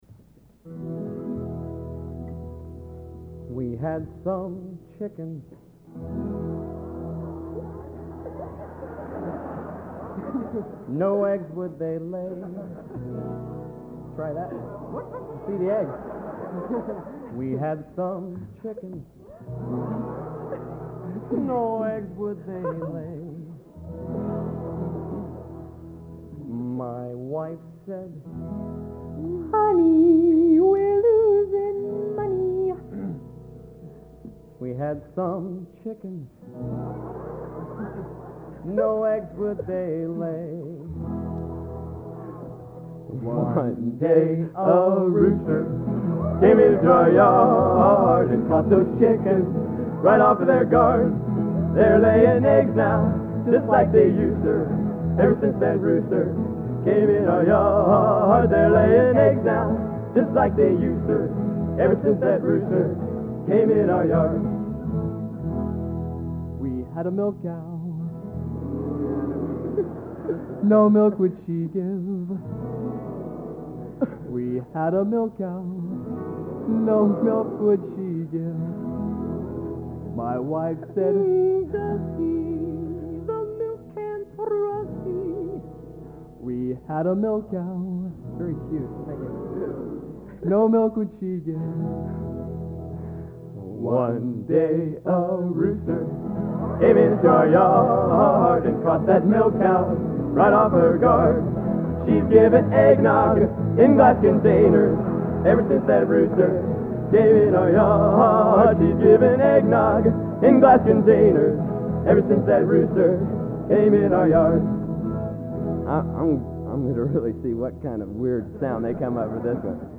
Location: West Lafayette, Indiana
Genre: Humor/Parody | Type: